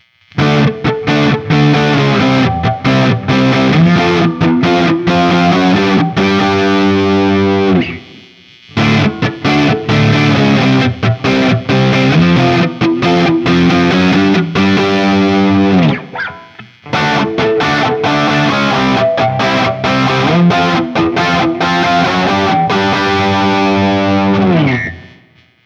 JCM-800
A Barre Chords
As usual, for these recordings I used my normal Axe-FX II XL+ setup through the QSC K12 speaker recorded direct into my Macbook Pro using Audacity.
For each recording I cycle through the neck pickup, both pickups, and finally the bridge pickup.
Middle string double stops or even an open A chord with high gain on the bridge pickup was reasonably snarly and even almost articulate but the overall dark nature of the tone just made it all kind of uninspiring.